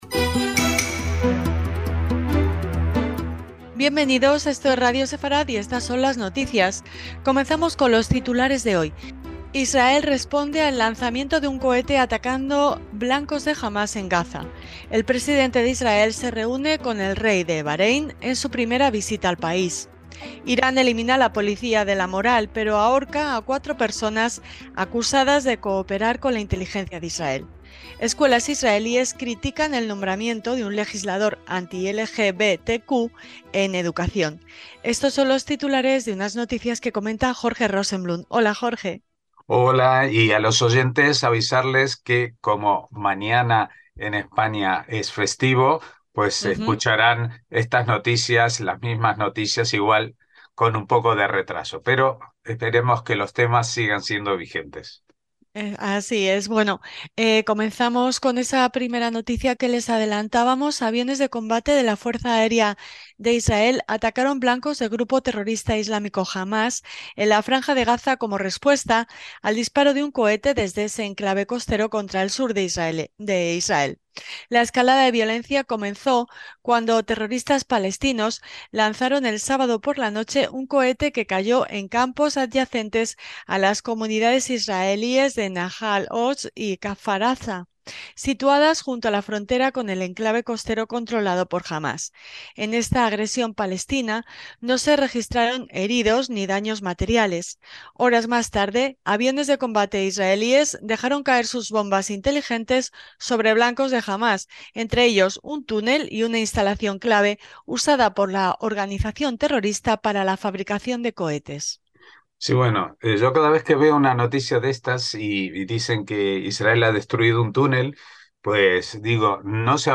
NOTICIAS – Titulares de hoy: Israel responde respuesta al lanzamiento de un cohete atacando blancos de Hamás en Gaza.